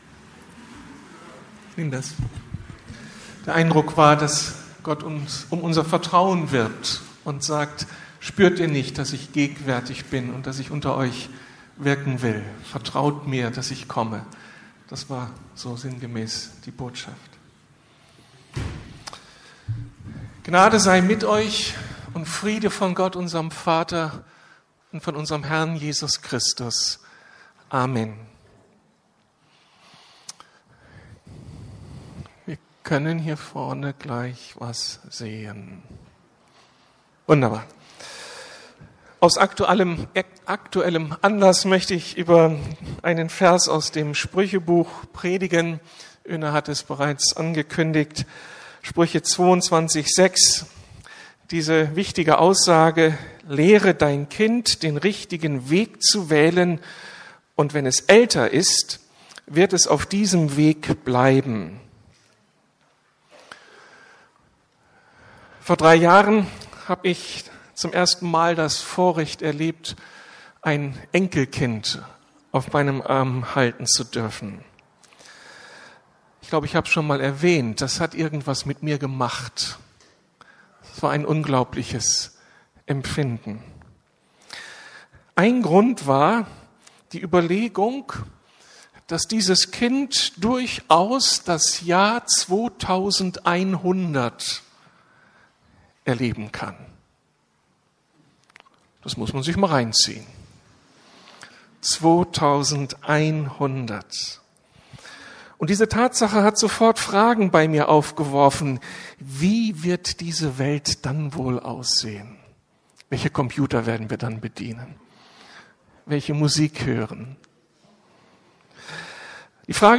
~ Predigten der LUKAS GEMEINDE Podcast